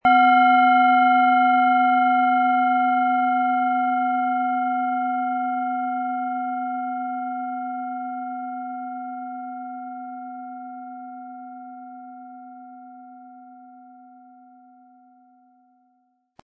Planetenton 1
Sie sehen eine Planetenklangschale DNA, die in alter Tradition aus Bronze von Hand getrieben worden ist.
Im Sound-Player - Jetzt reinhören hören Sie den Original-Ton dieser Schale. Wir haben versucht den Ton so authentisch wie machbar hörbar zu machen, damit Sie hören können, wie die Klangschale bei Ihnen klingen wird.
MaterialBronze